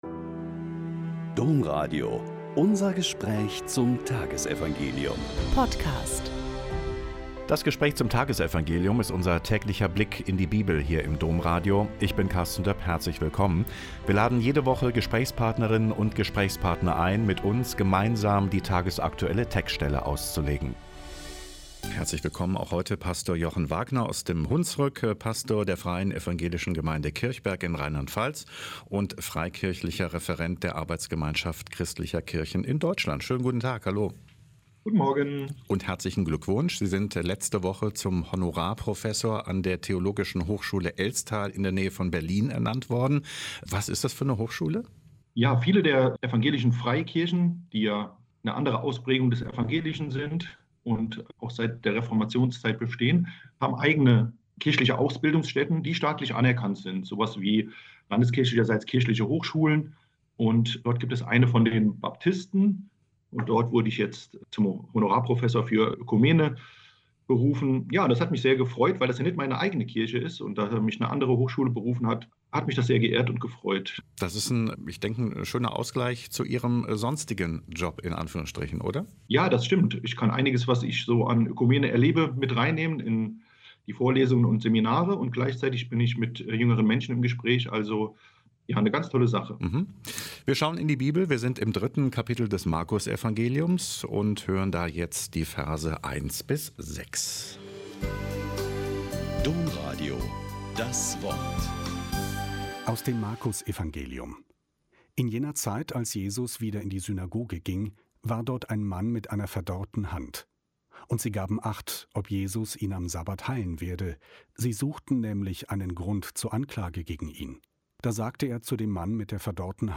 Mk 3,1-6 - Gespräch